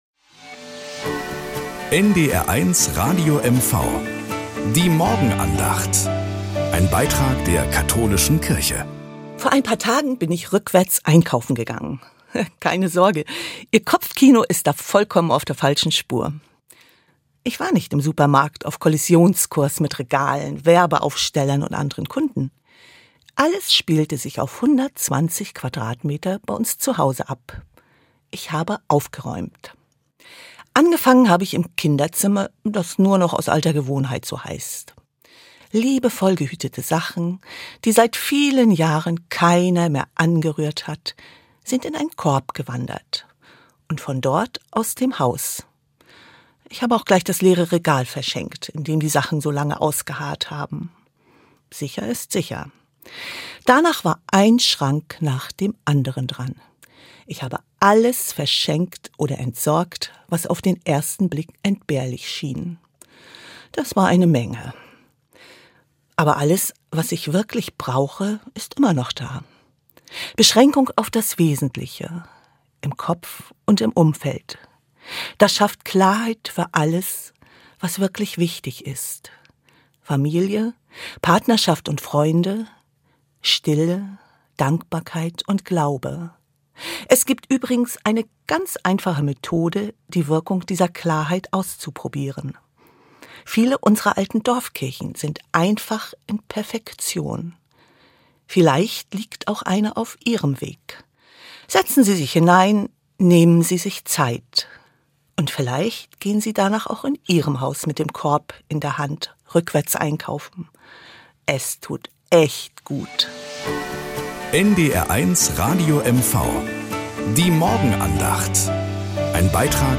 Morgenandacht bei NDR 1 Radio MV